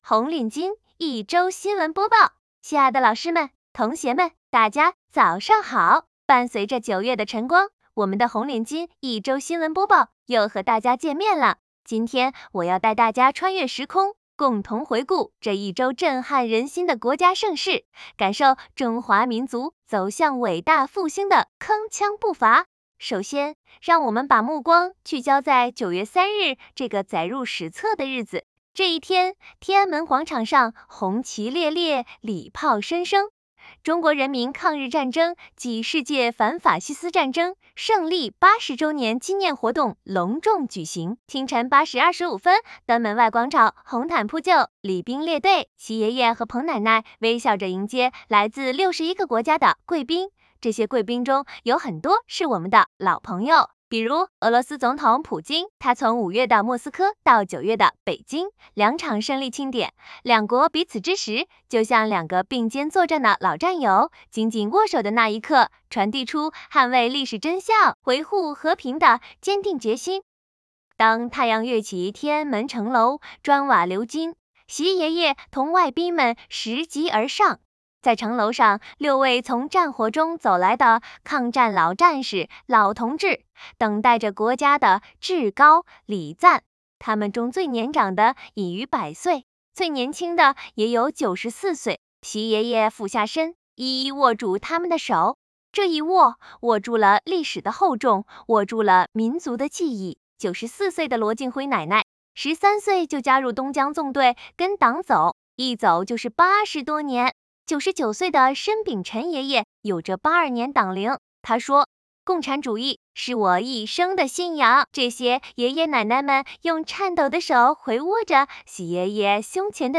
工作流-自动生成并播报每周新闻 先由python脚本自动获取新闻保存成json格式文件，交给Deepseek生成适合学生阅读的新闻稿，最后由CosyVoice2生成语音播报的工作流。